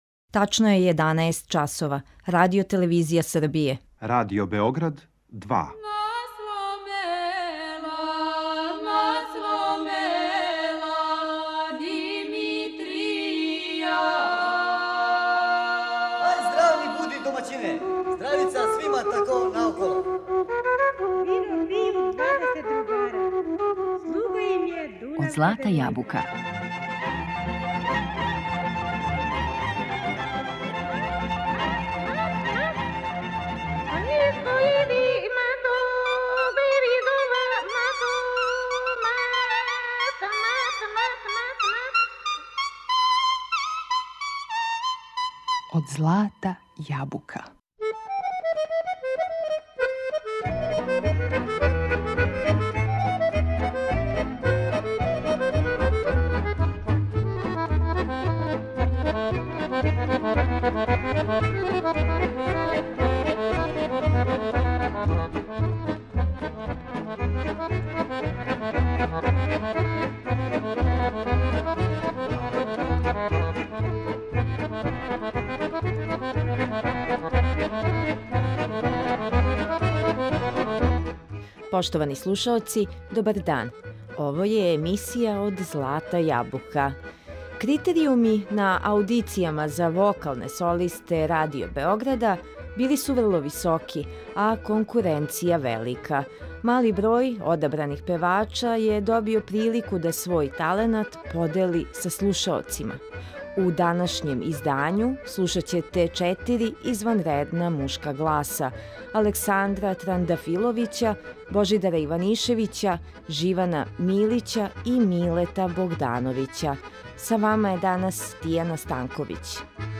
Najlepše narodne pesme u izvođenju vokalnih solista
U današnjem izdanju emisije Od zlata jabuka slušaćete četiri izvanredna muška glasa